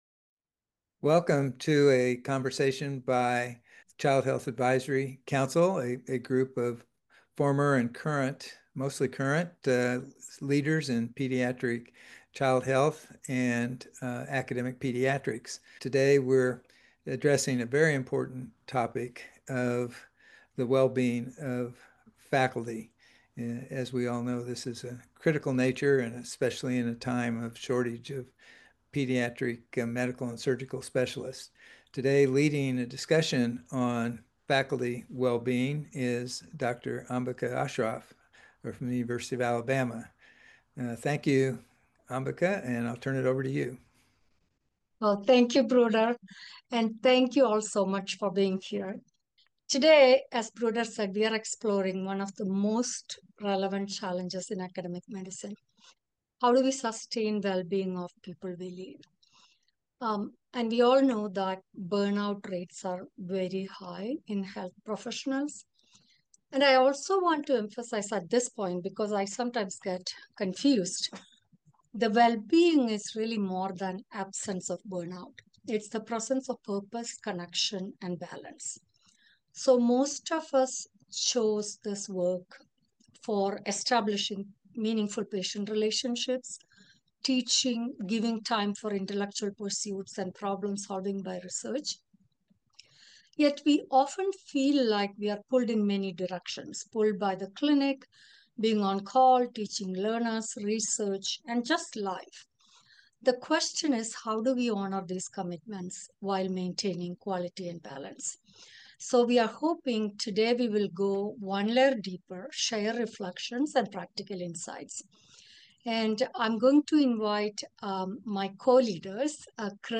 Drawing on real-world leadership experience and advisory insight from the Child Health Advisory Council’s pediatric specialty experts, this candid discussion offers practical perspective for leaders navigating change across generations.